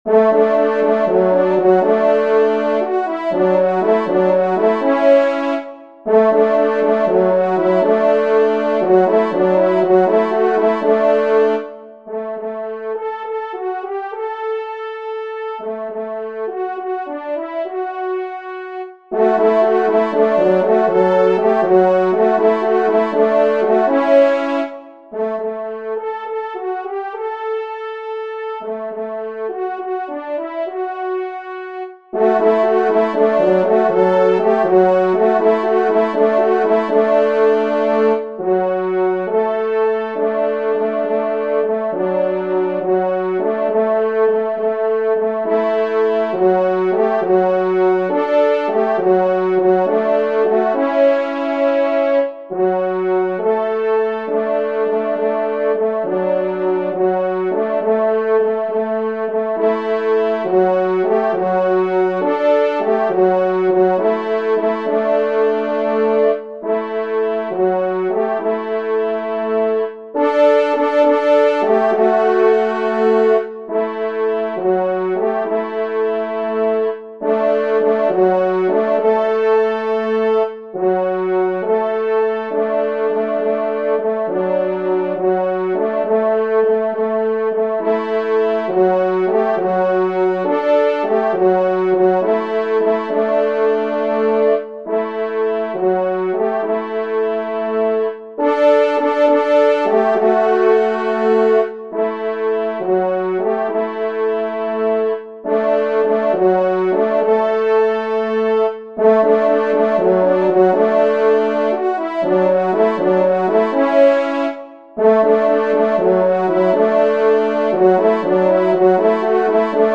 Genre :  Divertissement pour Trompes ou Cors
Pupitre 3°  Cor